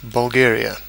Ääntäminen
Ääntäminen US UK UK : IPA : /bʌlˈɡɛə.ɹi.ə/ US : IPA : /bʌlˈɡɛɹ.i.ə/ IPA : /bʊlˈɡɛɹ.i.ə/ Lyhenteet ja supistumat (laki) Bulg.